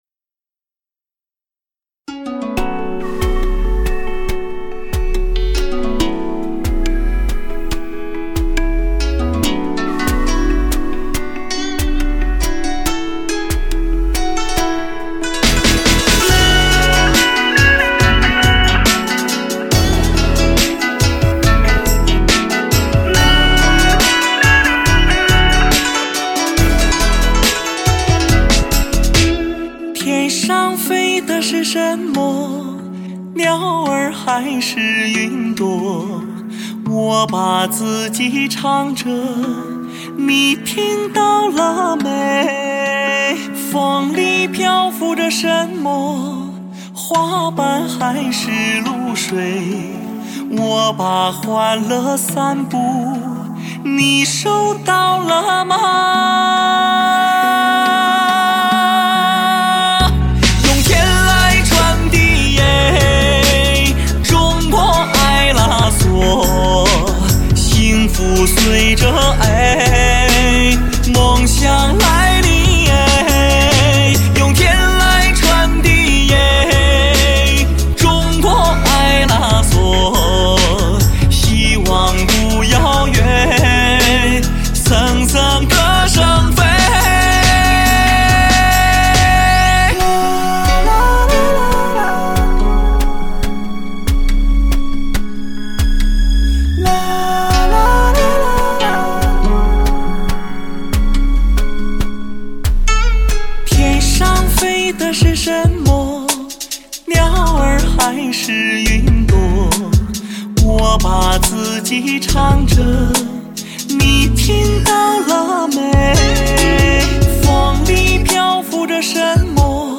极品流行发烧人声,挑战人体最高极限